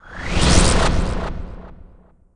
s_ef_ce_barrier.wav